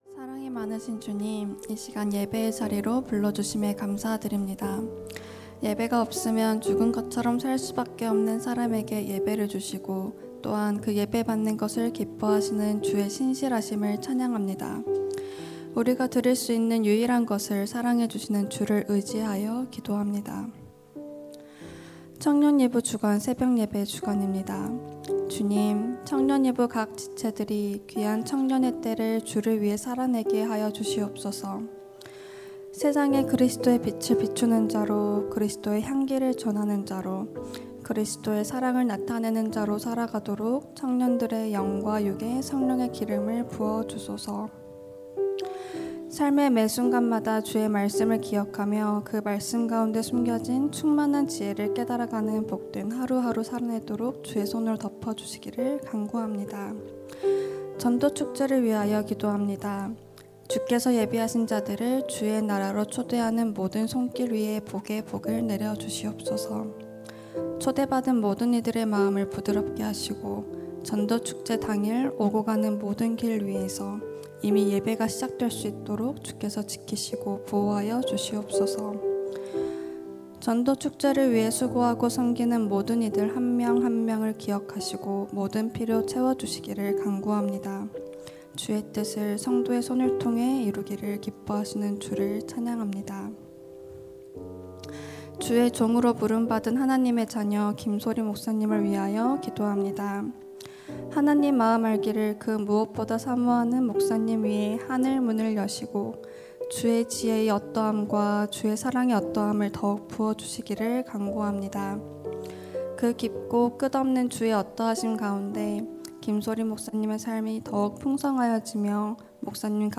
2025-10-29 새벽기도회